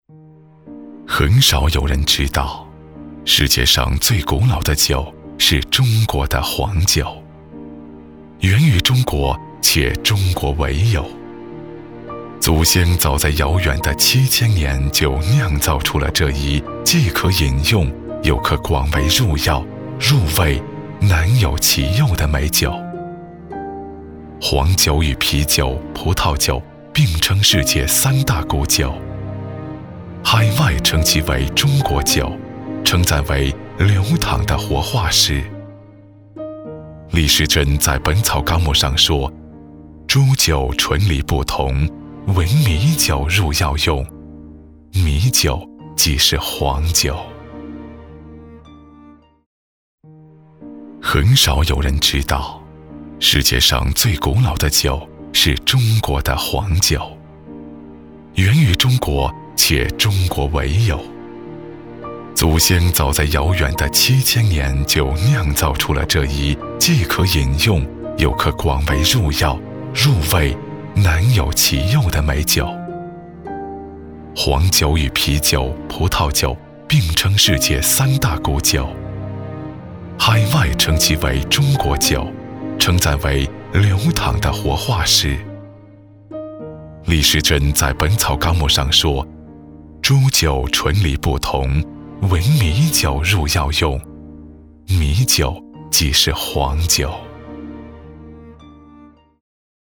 • 男S355 国语 男声 专题片-黄酒-美食专题-深沉浑厚 大气浑厚磁性|沉稳|科技感